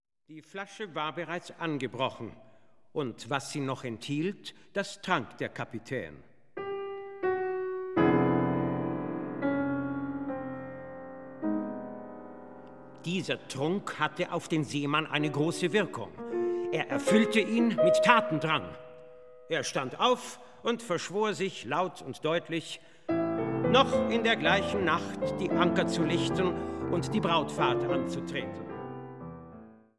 Klavier
Werke für Sprecher und Klavier.